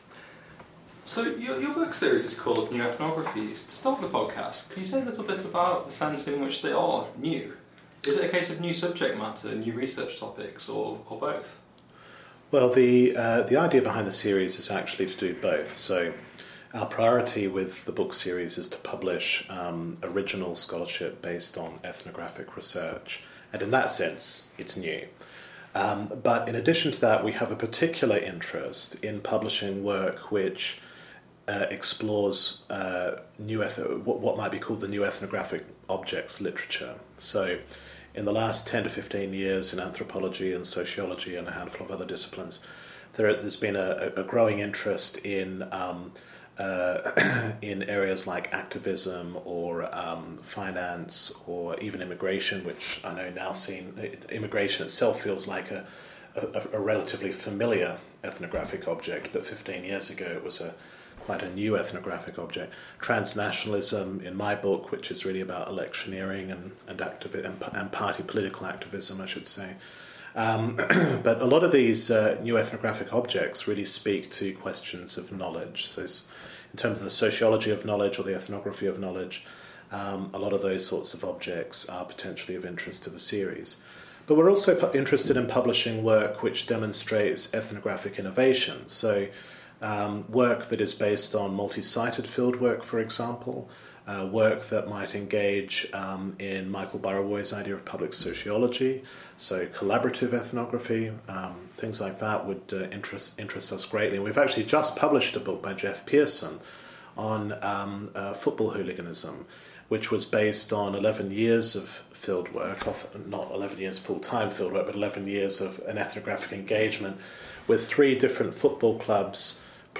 What does the future hold for ethnography? An interview